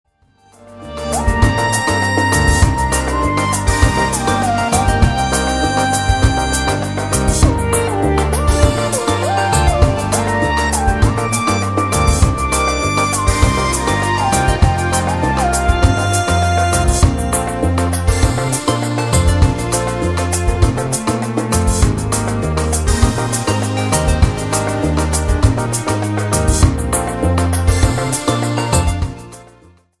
JAZZ  (03.57)